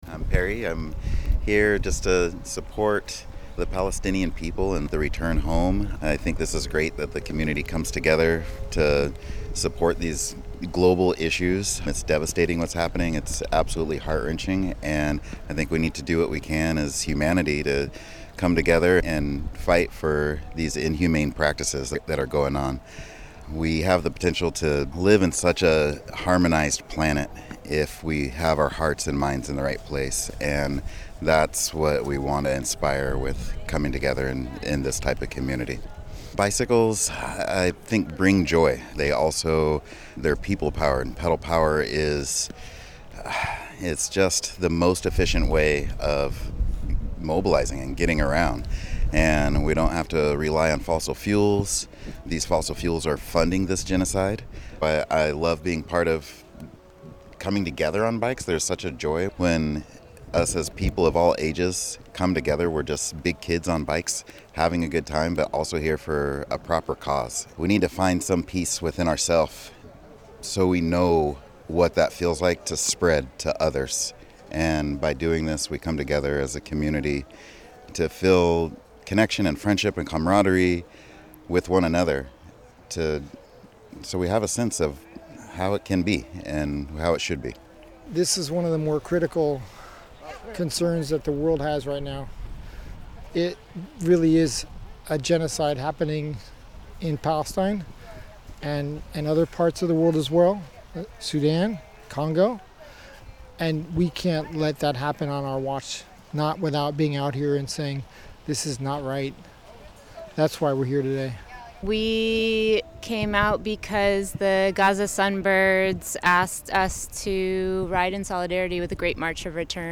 During the ride, the large group took over the road amid intermittent chants of “Free, free Palestine!” and honks and waves of approval from passersby.